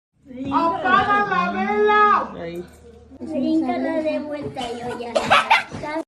apaga la vela Meme Sound Effect
This sound is perfect for adding humor, surprise, or dramatic timing to your content.